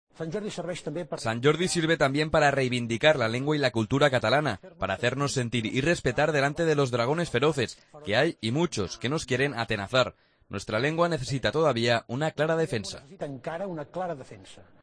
Discurso del presidente de la Generalitat en su primer Día de Sant Jordi.